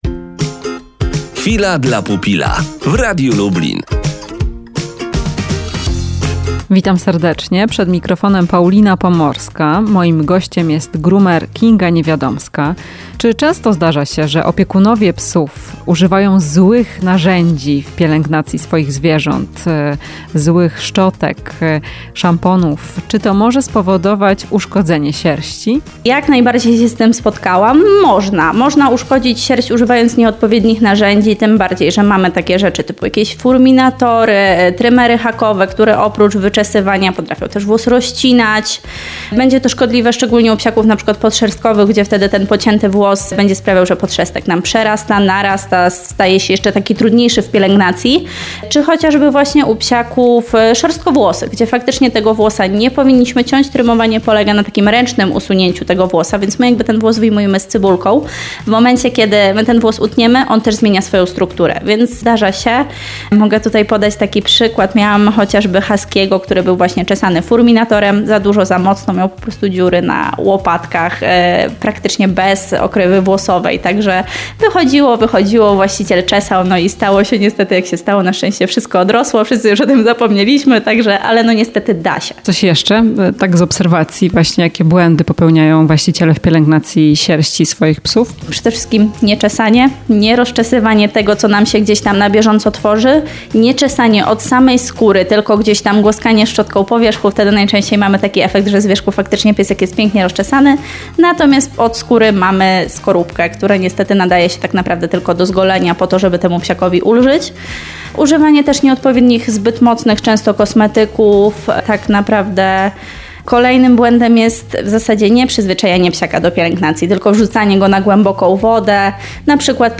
W "Chwili dla pupila" omawiamy jakie błędy w pielęgnacji psów najczęściej popełniają opiekunowie. Rozmowa